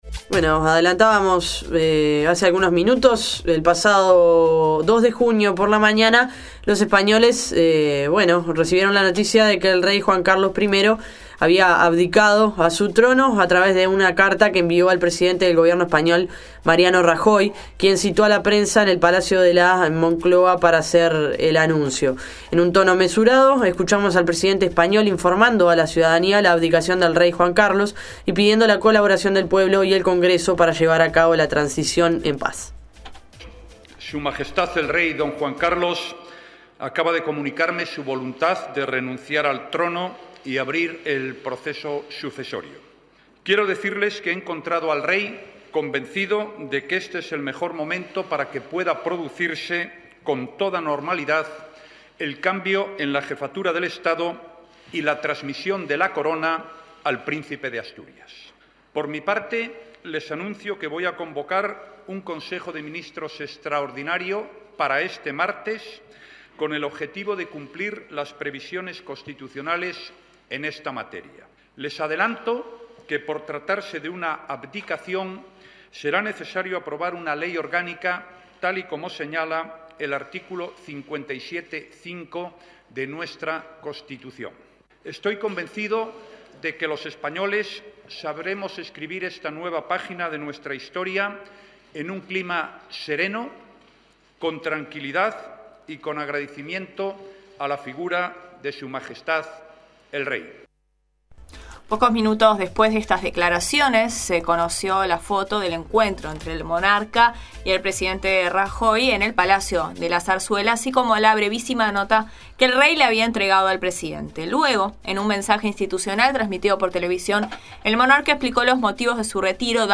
La Nueva Mañana entrevistó al Diputado Joan Josep Nuet, representante de Izquierda Unida en Catalunya, quien entre otras cosas comentó que actualmente más de la mitad de la población española estaría de acuerdo en ser consultada mediante referéndum si desean conservar la monarquía o pasar a ser una república. Esto se debe principalmente a los escándalos vividos durante los últimos años por parte de la familia real y los altos costes que le generan al país.